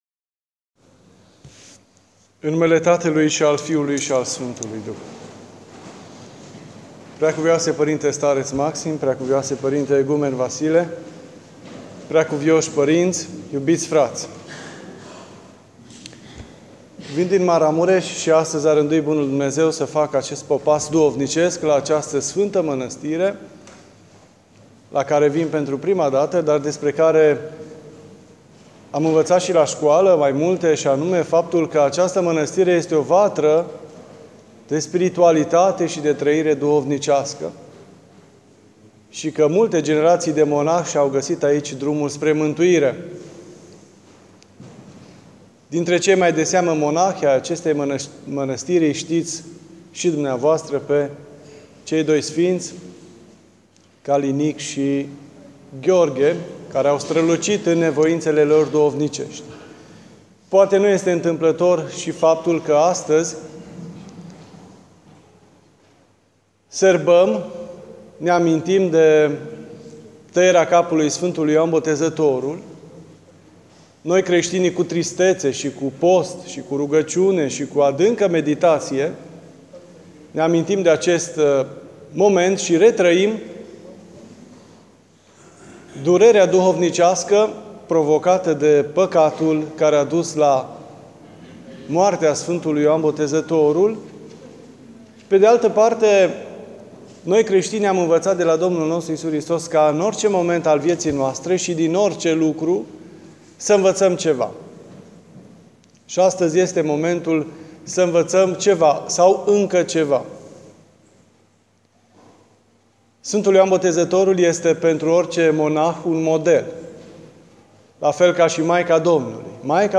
Despre desfrânare și despre martiriu (Cuvânt rostit la Mănăstirea Cernica)